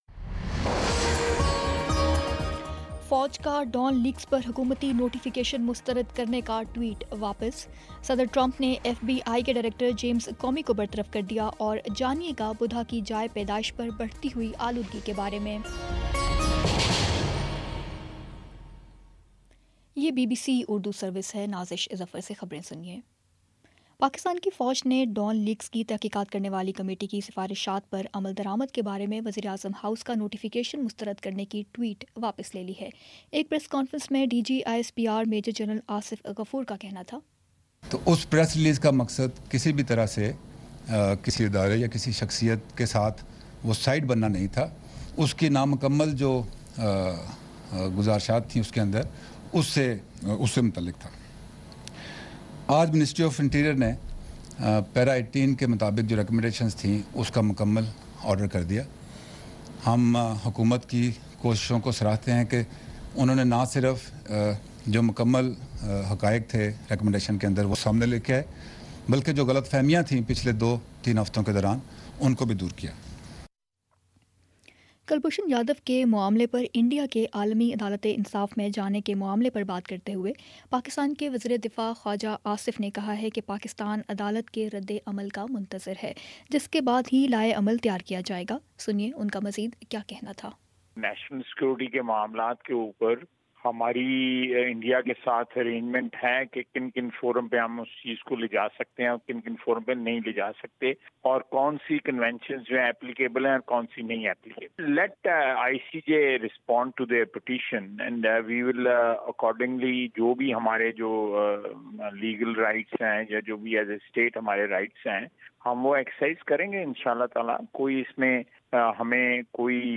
مئی 10 : شام چھ بجے کا نیوز بُلیٹن